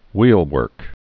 (wēlwûrk, hwēl-)